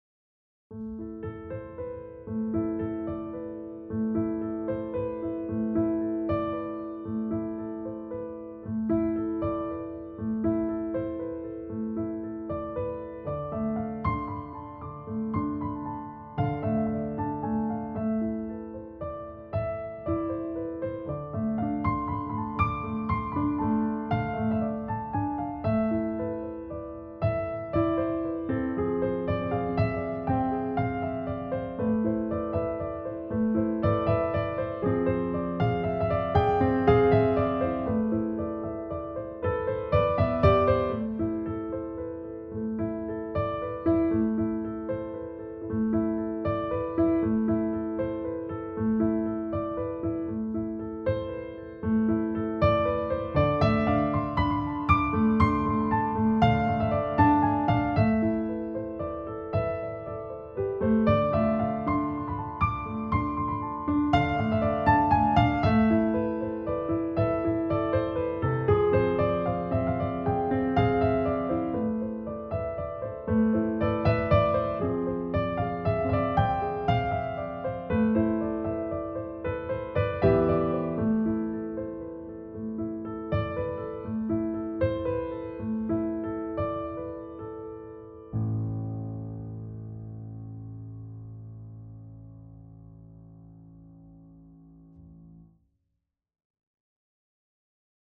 piano - calme - melodieux - melancolique - triste